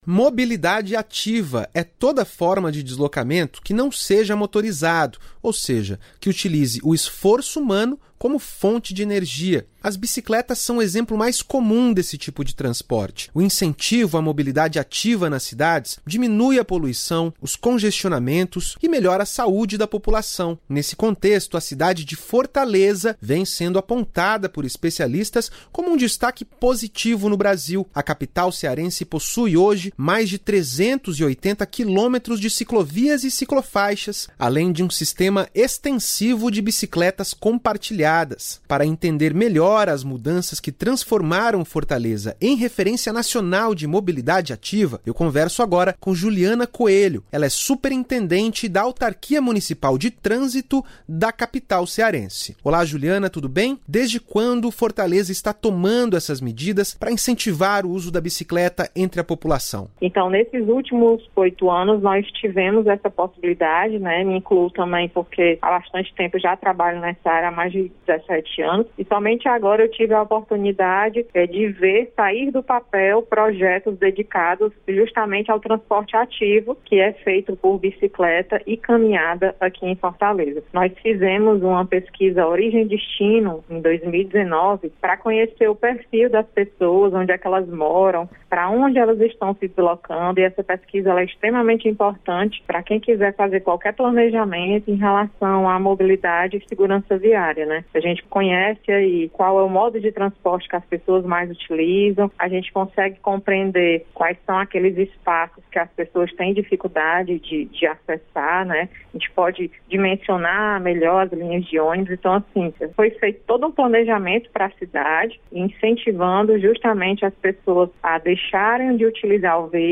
Entrevista com a superintendente da Autarquia Municipal de Trânsito, Juliana Coelho, detalha ações que transformam o sistema de mobilidade urbana da capital cearense.